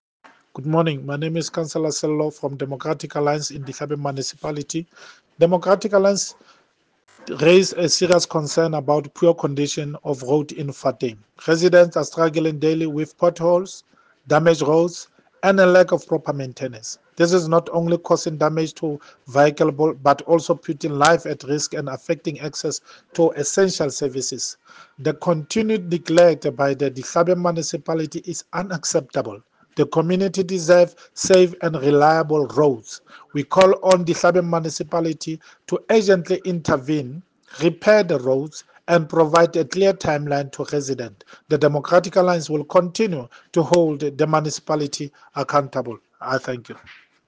Sesotho soundbites by Cllr Sello Makoena and